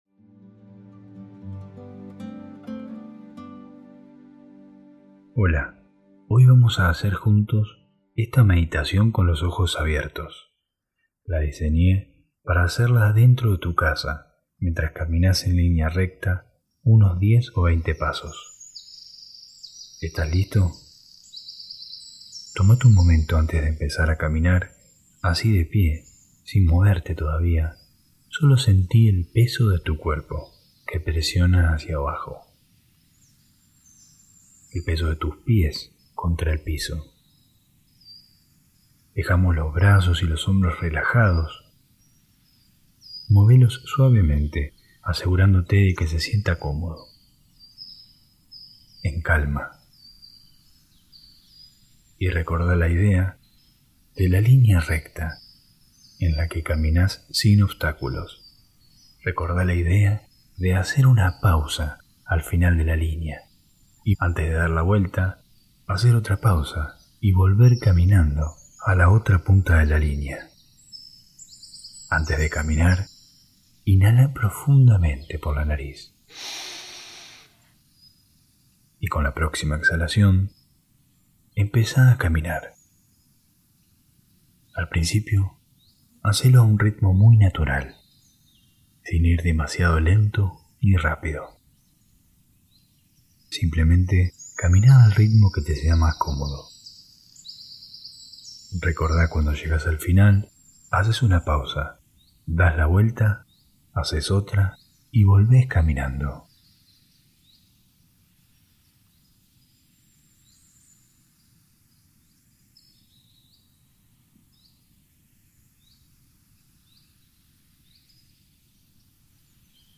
Este ejercicio de caminar en casa permite una mayor tolerancia al aislamiento y la ansiedad [Audio 8D. Mejor con auriculares] Hosted on Acast.